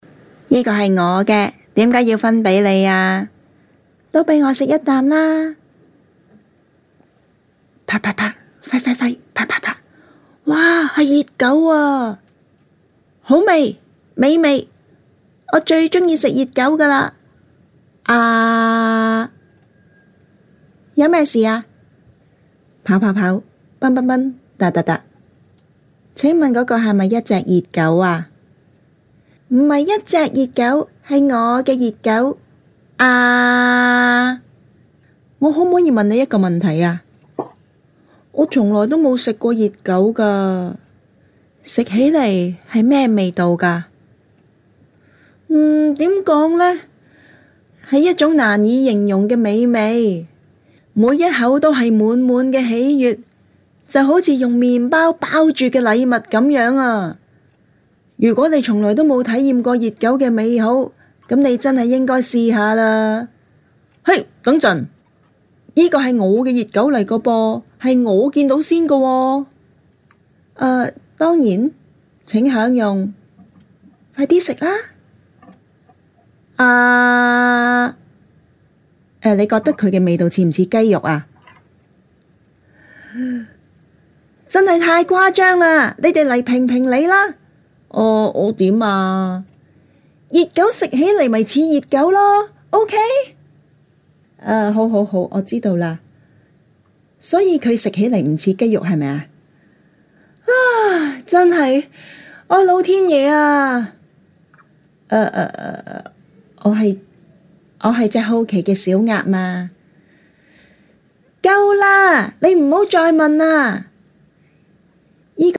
港式粤语配音
• 女粤03 香港粤语港式粤语女声 自然对话 沉稳|娓娓道来|科技感|积极向上|时尚活力|神秘性感|调性走心|亲切甜美|感人煽情|素人